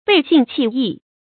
背信弃义 bèi xìn qì yì
背信弃义发音
成语正音 背，不能读作“bēi”。